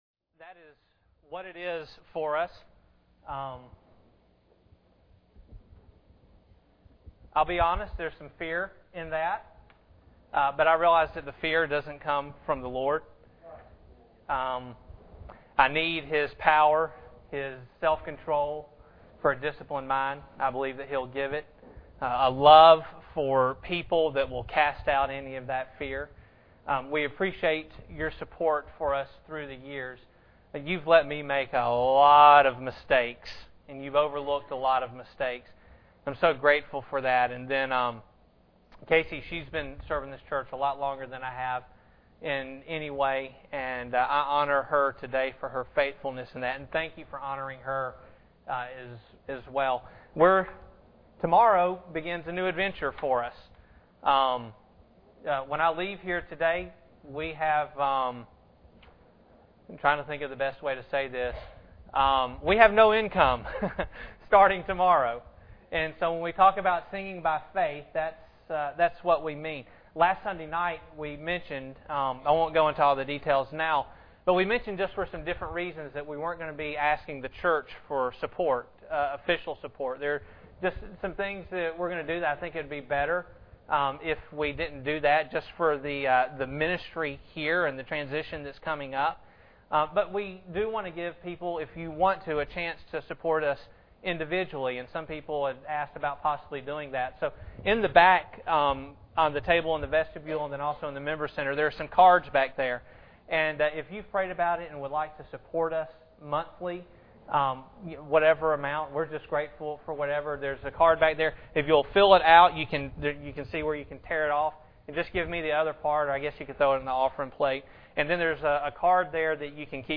Hebrews 11:24-26 Service Type: Sunday Morning Bible Text